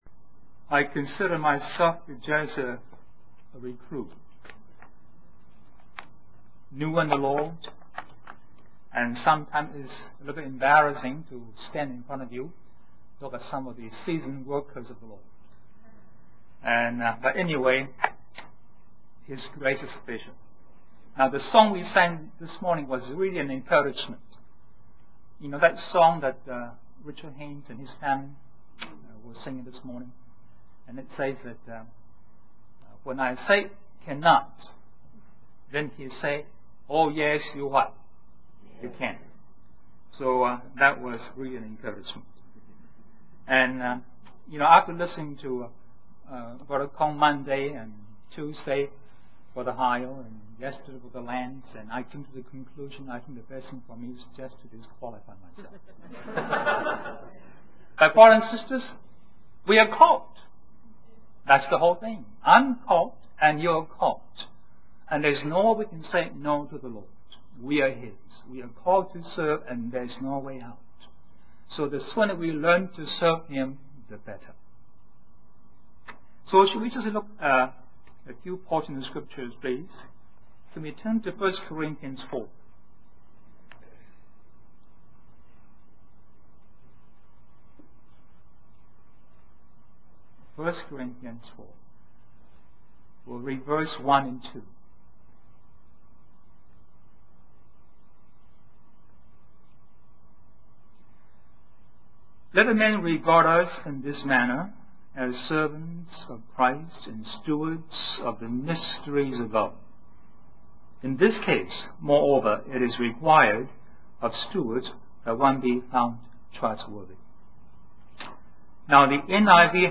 In this sermon, the preacher emphasizes the importance of serving the Lord wholeheartedly. He refers to 1 Corinthians 4:1-2, which teaches that believers are called to be servants of Christ and stewards of His mysteries. The preacher emphasizes the need for trustworthiness in stewardship.